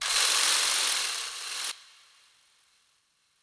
Fx (Drill).wav